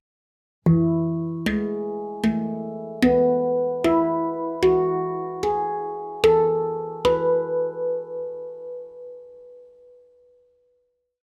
Moon II Handpan i E-dur (Ø 55 cm) er laget av rustfritt stål og byr på en klar, lys tone med lang sustain.
• Stemt i E-dur for en frisk og harmonisk klang.
• Lang sustain og rask toneutvikling.
E3, G#3, A3, B3, E4, F#4, G#4, A4, B4